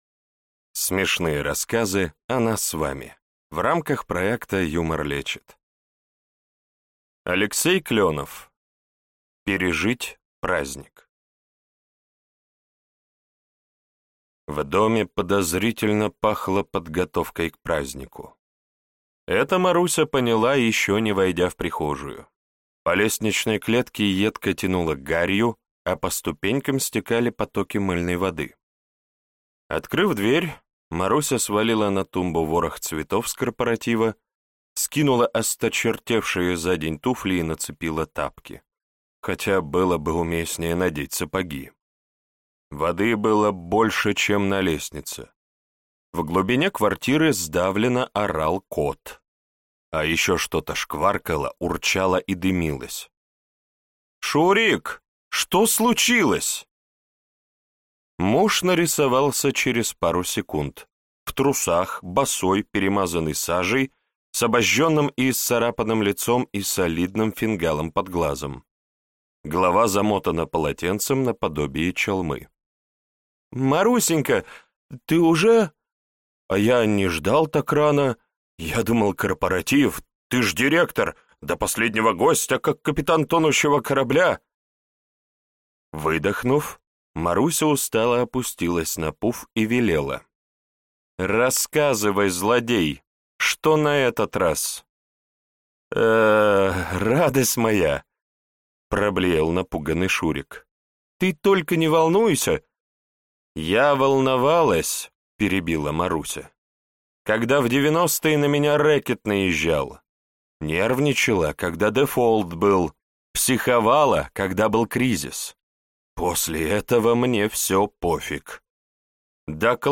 Аудиокнига Смешные рассказы о нас с вами | Библиотека аудиокниг